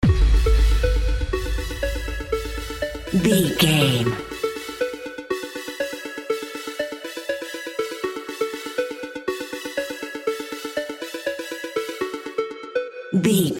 Aeolian/Minor
F#
groovy
uplifting
driving
energetic
synthesiser
drum machine
house
electro dance
synth leads
synth bass
upbeat